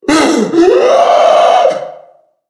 NPC_Creatures_Vocalisations_Puppet#2 (hunt_02).wav